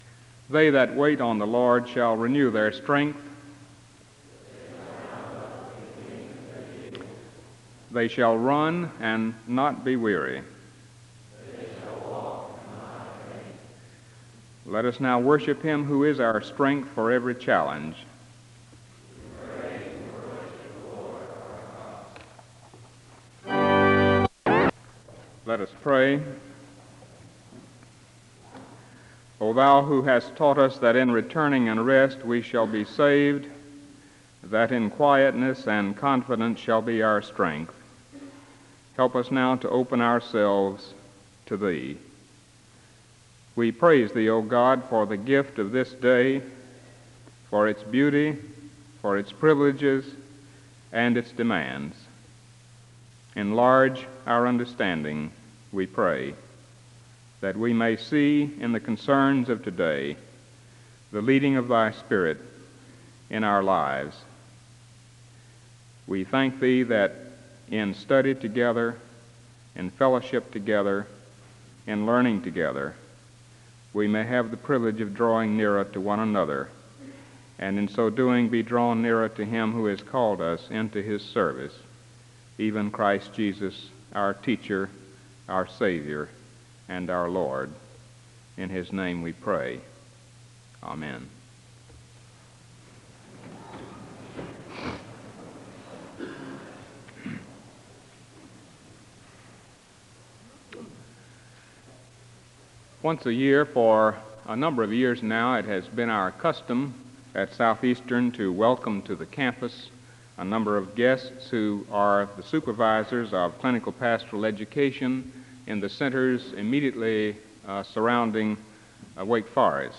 The service begins with a responsive reading and a word of prayer (00:00-01:32).
The choir sings a song of worship (04:51-09:17).
The service ends with a word of prayer (23:17-23:55).